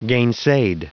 Prononciation du mot gainsaid en anglais (fichier audio)
Prononciation du mot : gainsaid